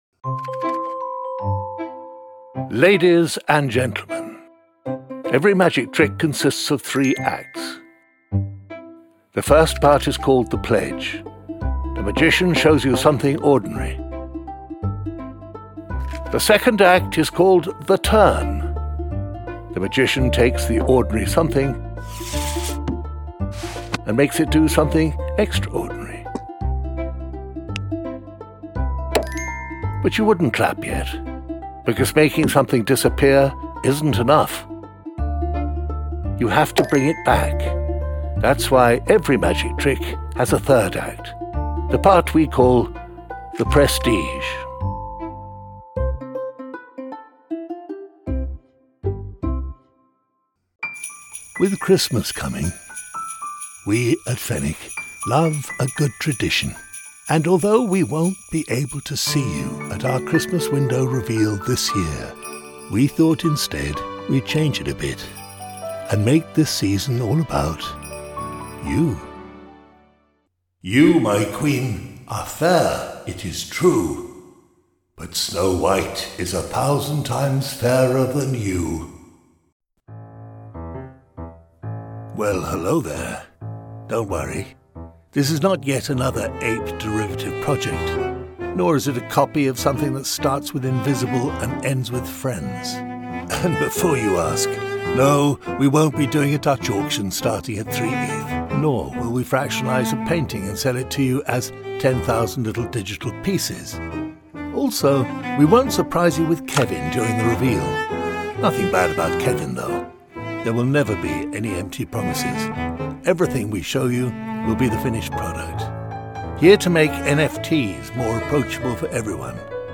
Skilled voice artists featured in Eargo commercials, delivering clear, confident reads for hearing-health messaging.